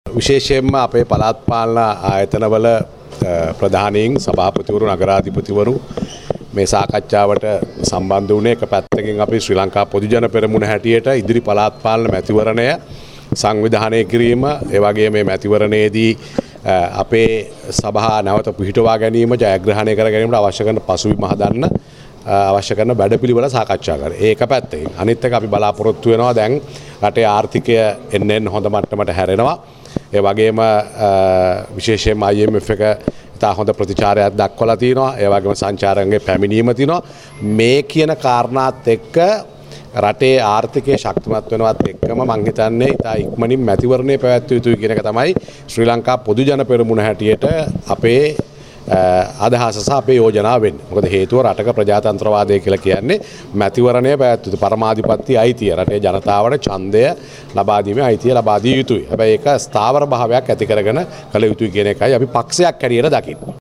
මේ පළාත් පාලන නියෝජිතයින්ගේ සාකච්ඡාවෙන් පසුව මාධ්‍යට අදහස් දැක්වු පාර්ලිමේන්තු මන්ත්‍රී රෝහිත අබේගුණවර්ධන මහතා.
මැයි දිනය සැමරීමට ශ්‍රී ලංකා පොදුජන පෙරමුණ සුදානම්දැයි මෙහිදී මාධ්‍යවේදියෙකු මන්ත්‍රිවරයාගෙන් විමසා සිටියා.
ROHITHA-ABEGUNAWARDANA-01.mp3